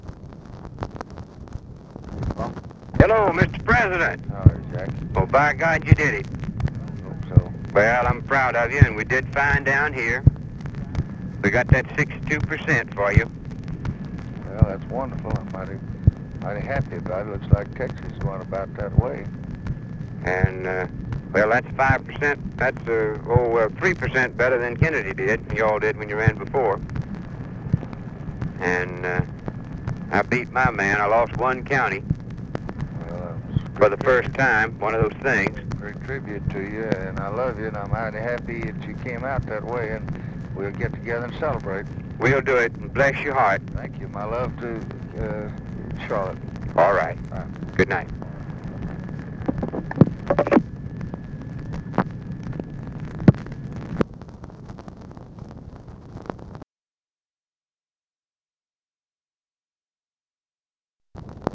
Conversation with JACK BROOKS, November 4, 1964
Secret White House Tapes